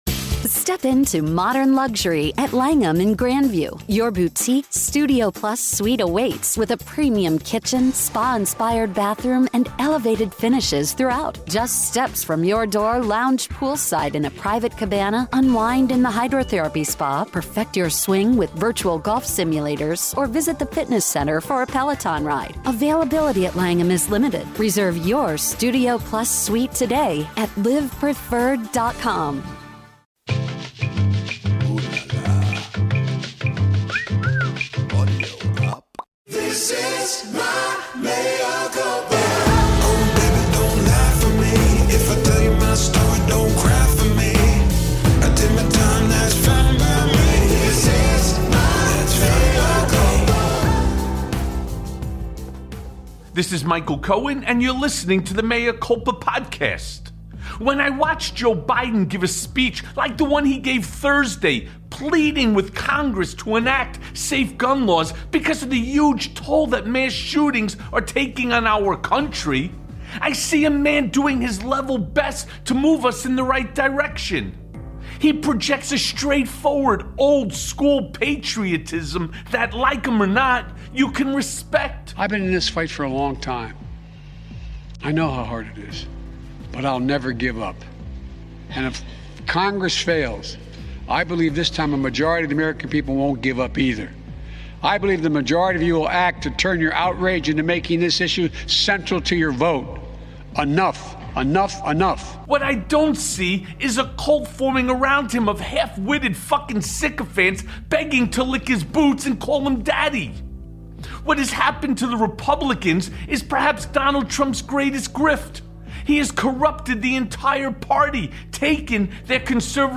How the GOP Plans to Sow Chaos in the 2020 Midterms + A Conversation With Norm Eisen
Norm is an attorney and author who has served in many government roles, including special counsel and special assistant to President Barack Obama for ethics and government reform — in that role Eisen was dubbed “Mr. No” and the “Ethics Czar”. Join us on Mea Culpa as Michael gets the scoop on the January 6th committee's planned actions and intents from Norm Eisen.